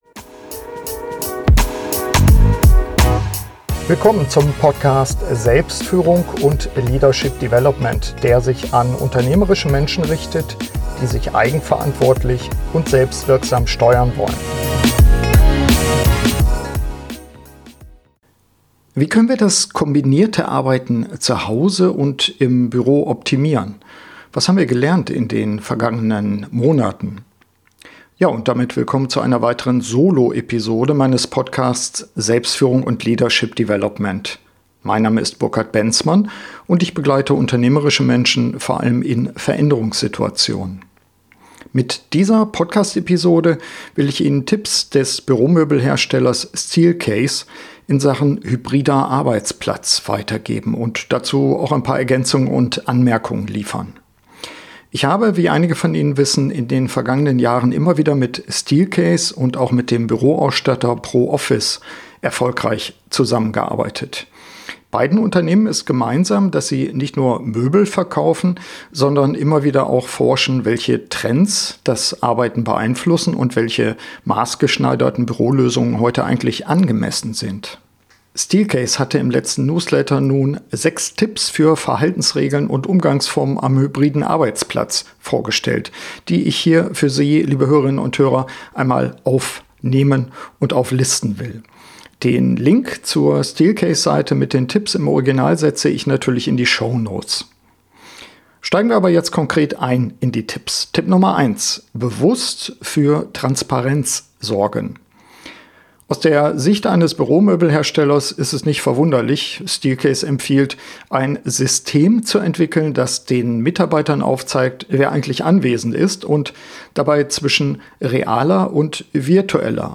Der Büromöbelhersteller Steelcase forscht zum hybriden Arbeiten. In seinem aktuellen Newsletter werden sechs Tipps vorgestellt, die ich in dieser Podcast-Soloepisode erörtere und ergänze.